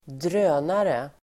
Ladda ner uttalet